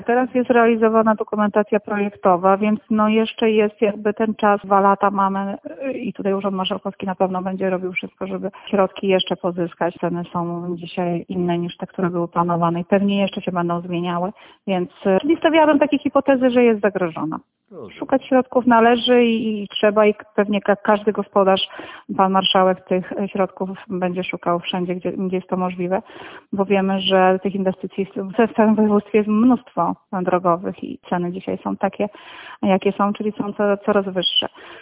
– Mówi Marzena Podzińska – Burmistrzyni Pyrzyc.